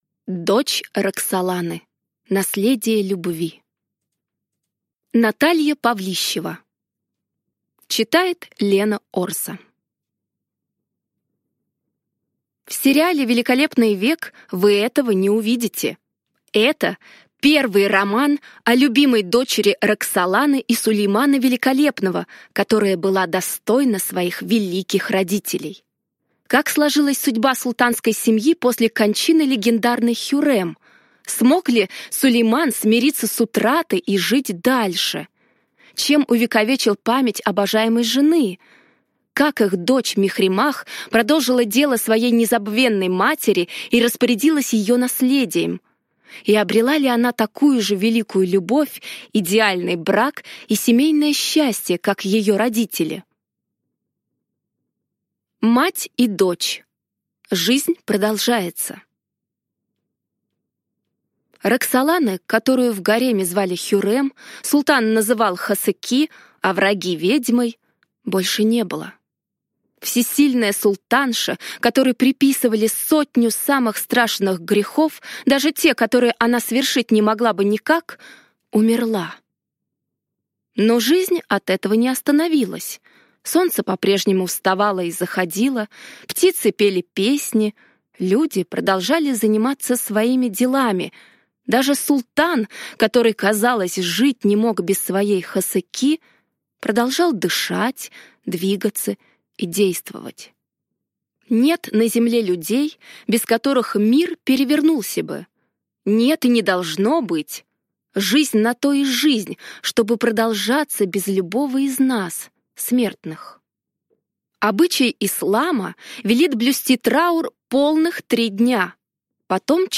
Аудиокнига Дочь Роксоланы. Наследие любви | Библиотека аудиокниг